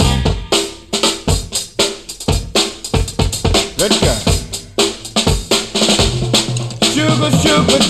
• 119 Bpm Drum Loop Sample C Key.wav
Free breakbeat - kick tuned to the C note. Loudest frequency: 3323Hz
119-bpm-drum-loop-sample-c-key-7gx.wav